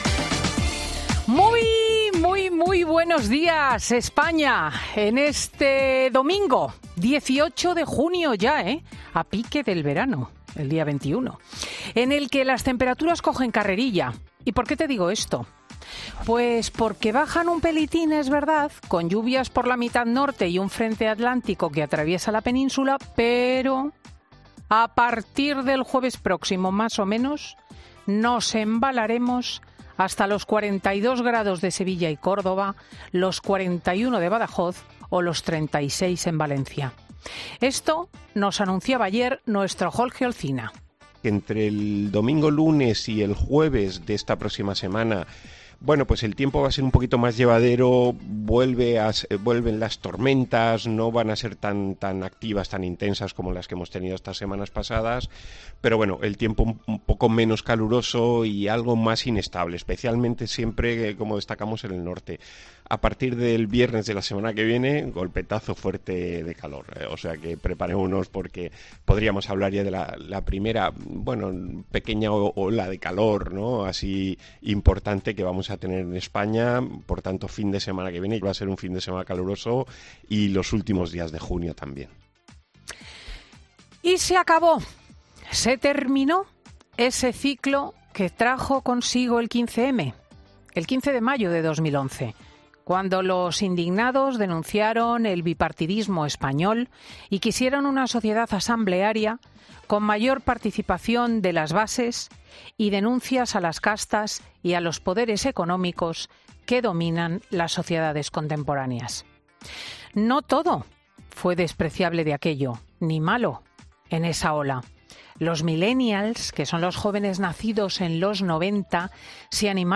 Ya puedes escuchar el monólogo de Cristina López Schlichting de este domingo 18 de junio de 2023